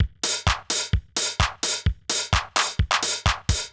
CLF Beat - Mix 5.wav